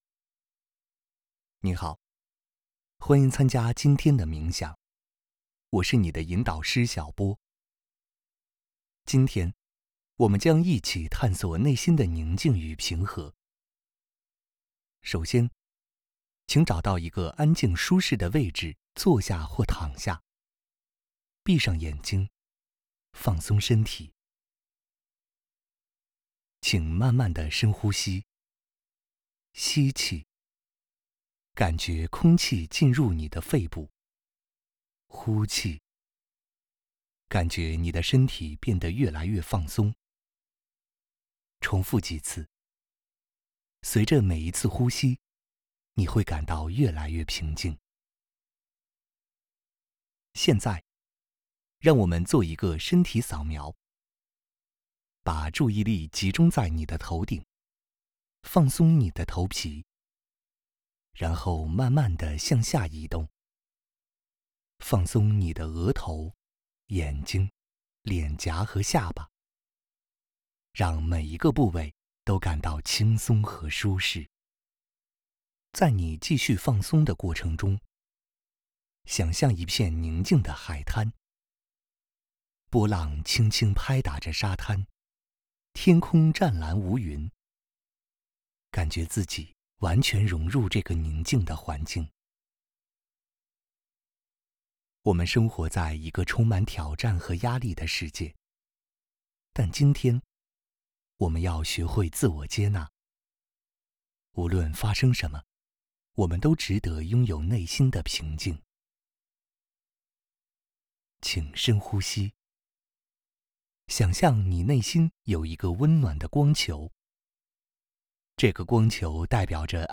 Chinese_Male_005VoiceArtist_20Hours_High_Quality_Voice_Dataset
Calm Style Sample.wav